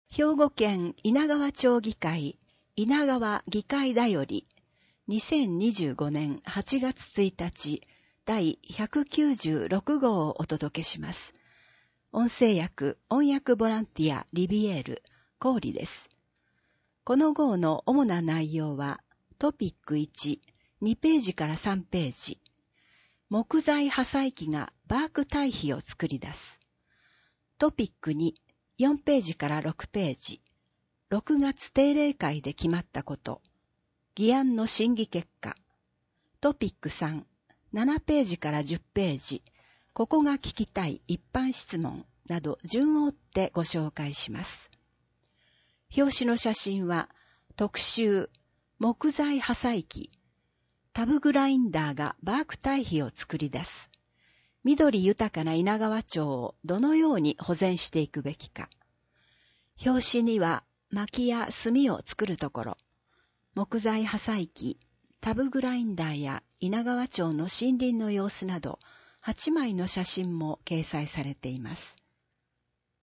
制作は猪名川町社会福祉協議会 音訳ボランティア リヴィエールの方々の協力によるものです。